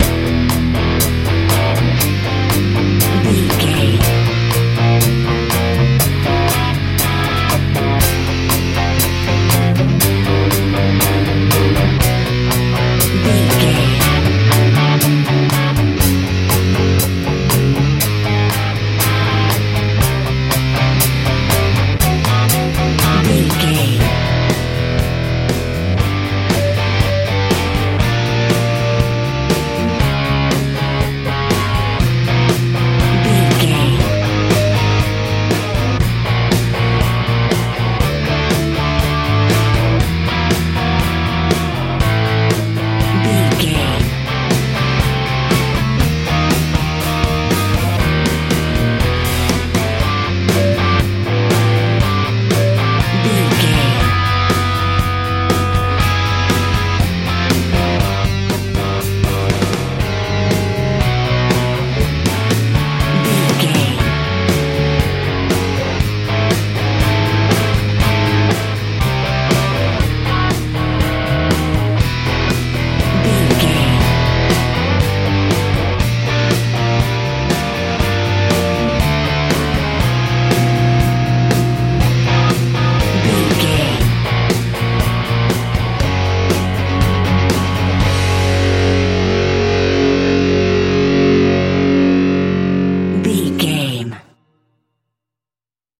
nu metal punk
Ionian/Major
disturbing
confused
electric guitar
drums
bass guitar
groovy
lively
energetic